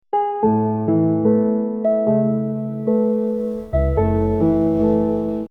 我要男生演唱一首关于和平的歌